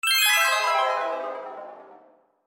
retro_game_over_2.mp3